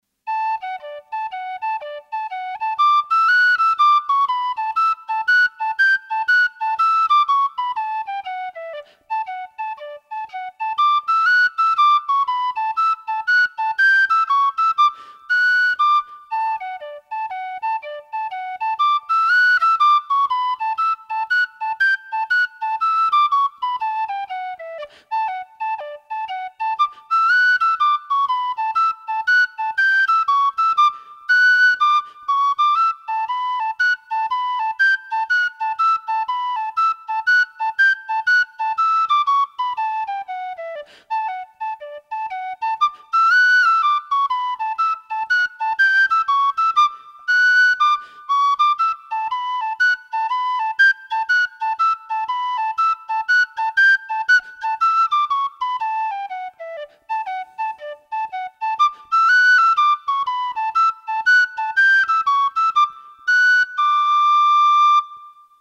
EATMS Tune book recording